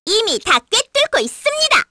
Hanus-Vox_Skill1_kr_b.wav